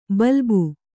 ♪ balbu